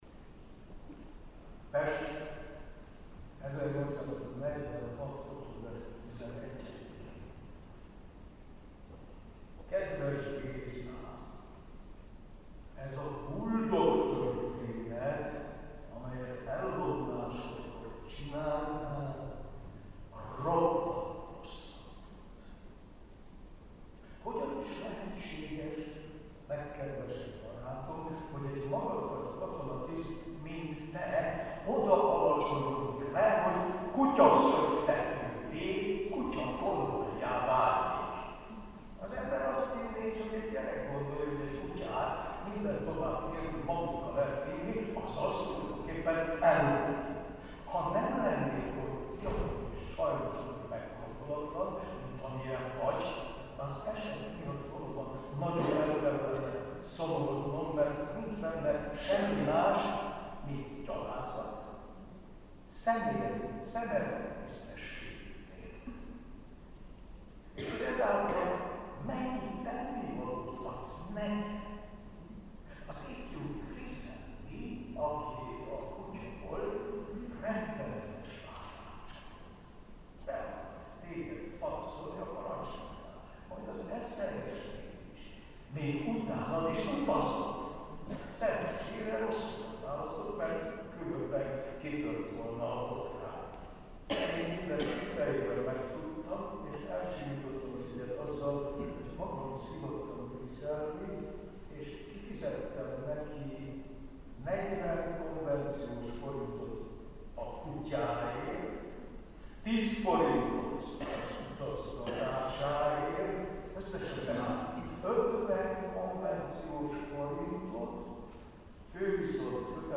Az MTA BTK Történettudományi Intézetének szervezésében került sor 2014. december 10-én a Magyar Tudományos Akadémia kupolatermében gróf Széchenyi István válogatott leveleinek bemutatására.
lenyűgöző értelmezéssel olvasott fel a kötetből leveleket.